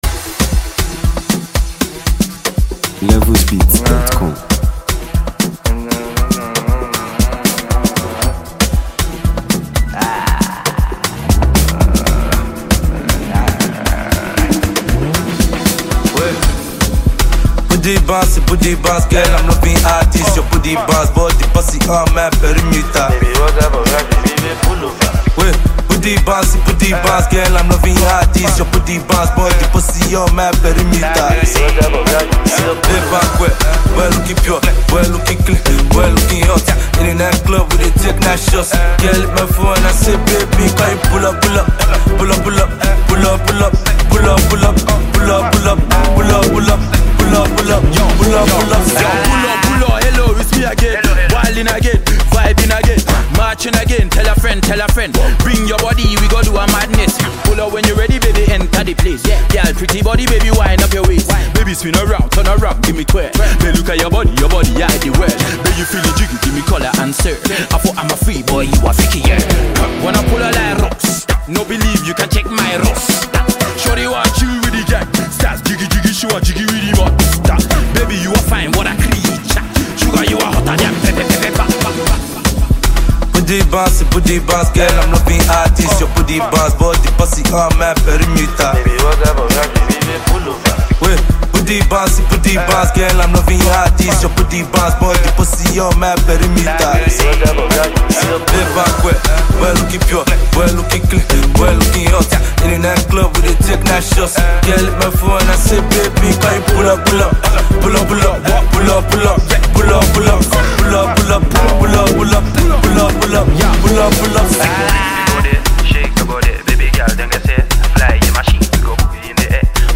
offering infectious rhythms, smooth melodies